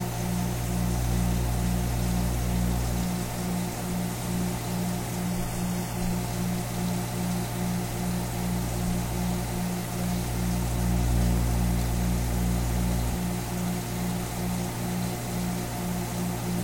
描述：closing the freezer compartment of a fridge/freezer.
标签： kitchen refrigerator thud home close slide freezer fridge household door